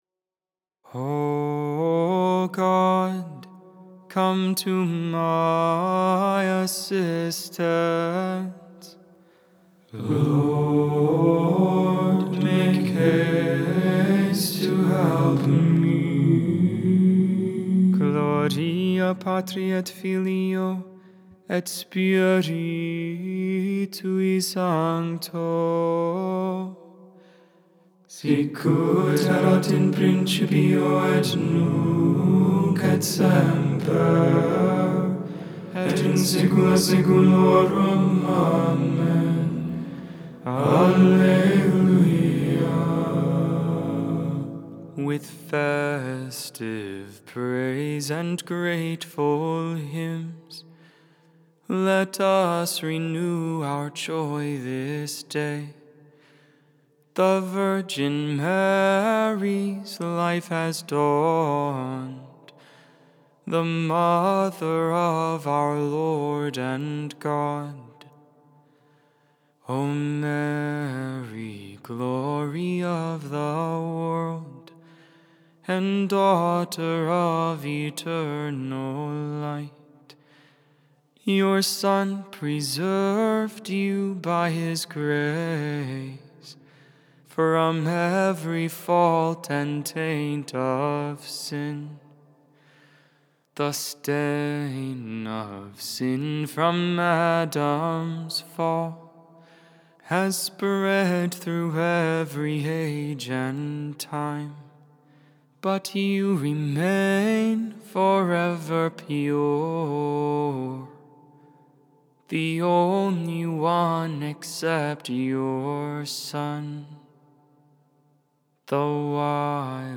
Tone 3